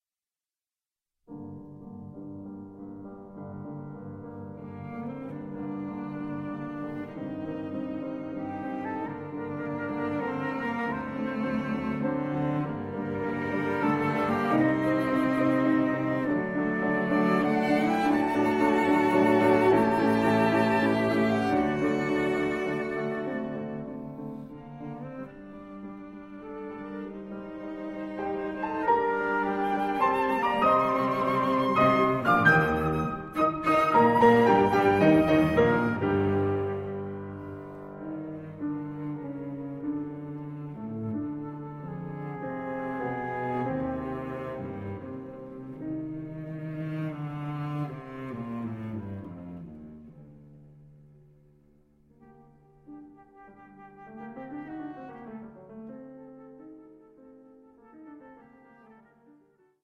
Allegro Moderato